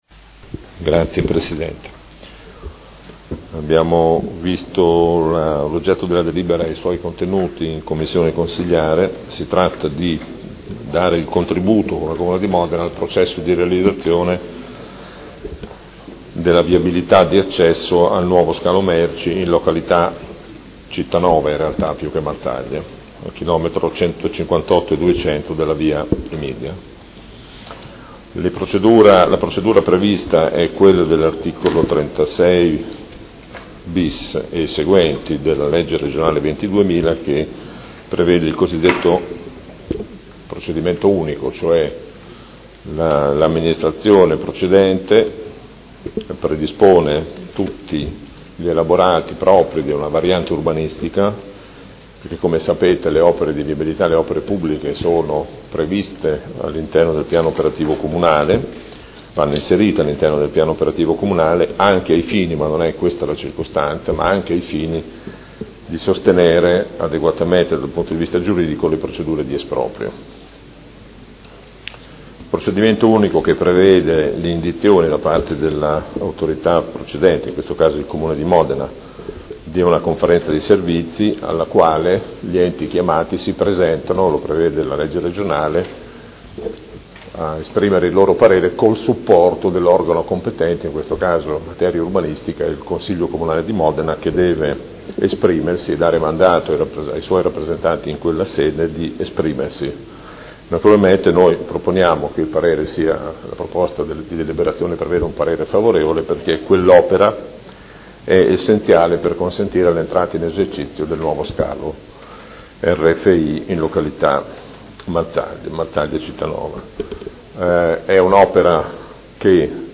Gabriele Giacobazzi — Sito Audio Consiglio Comunale
Seduta del 3 marzo.